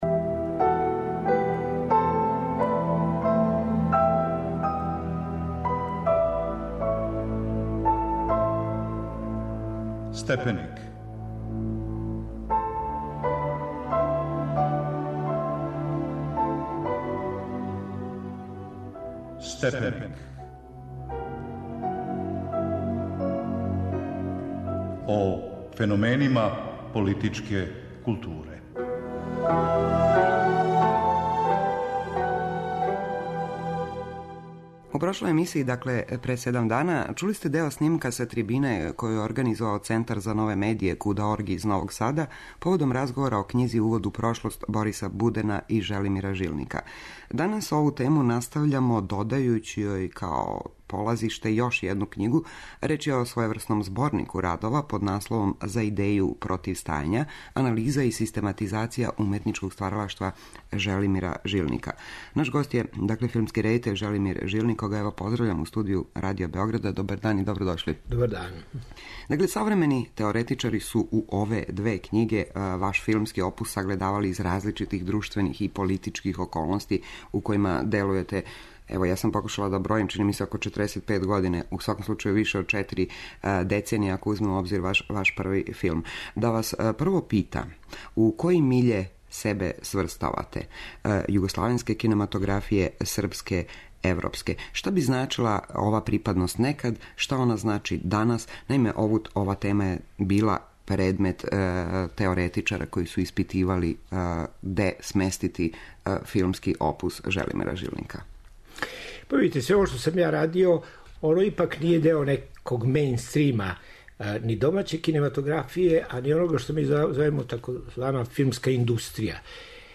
Садржаји књига "За идеју - против стања" и "Увод у прошлост" (издавач куда. орг, Нови Сад), које нуде и ретку архивску грађу из Жилникове личне документације, биће укључени као својеврсна полазна основа у разговор о редитељској и активистичкој пракси Желимира Жилника.